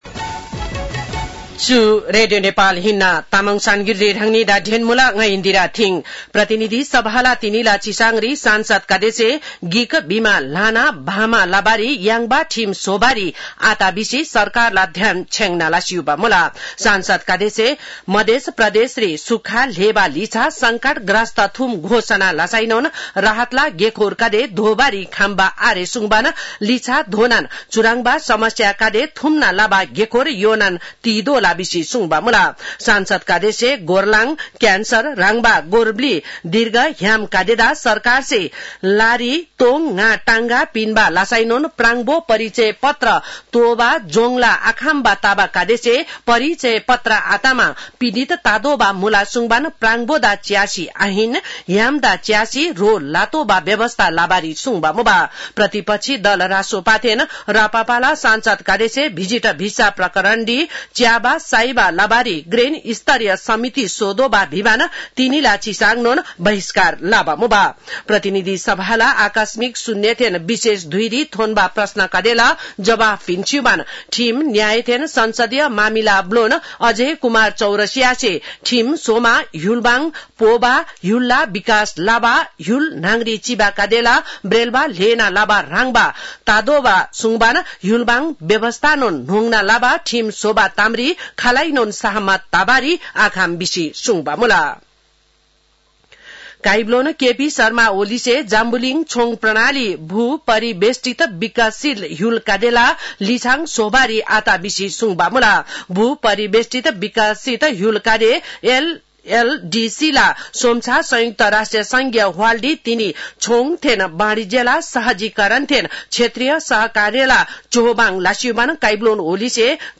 तामाङ भाषाको समाचार : २१ साउन , २०८२
Tamang-news-4-21.mp3